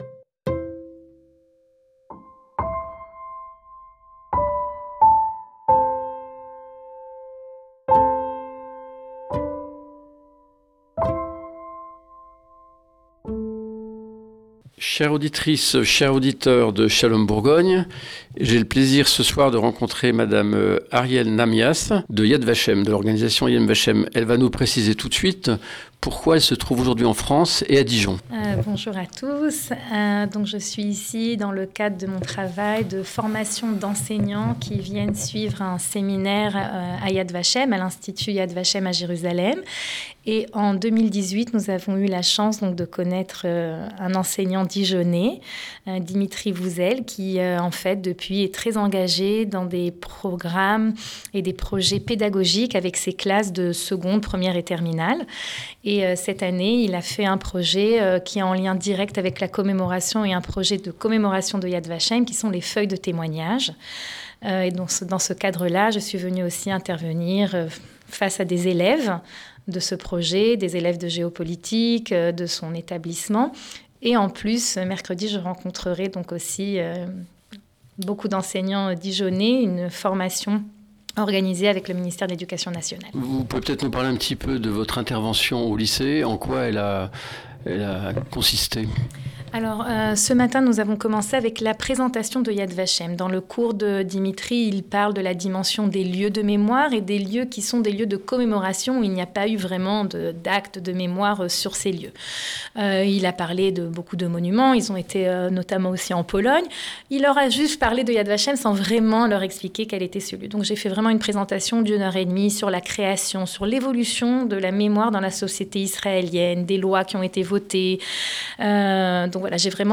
Nous luis avons demandé de nous présenter YAD VASHEM, l’Institut international pour la mémoire de Shoah, les lieux, les missions et l’évolution. Il est aussi question du 7 octobre au regard de la mission de l’Institut et de la rigueur de ses travaux et de son enseignement. Interview